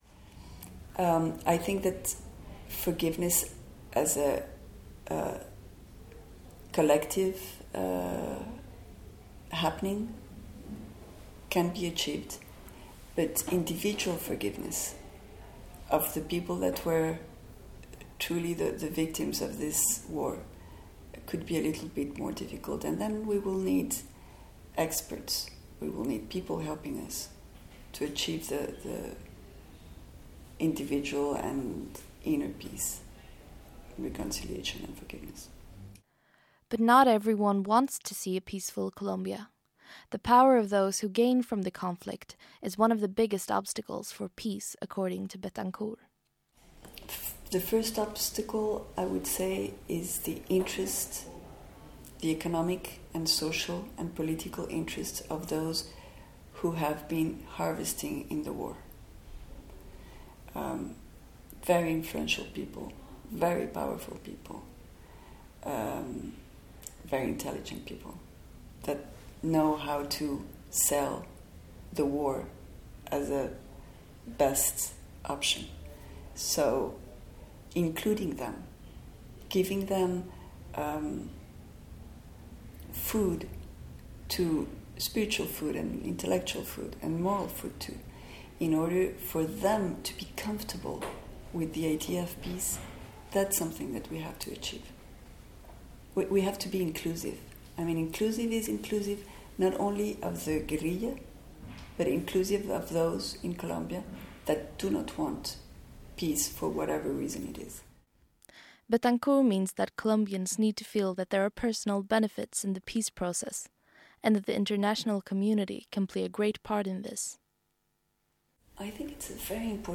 Interview with Ingrid Betancourt part 2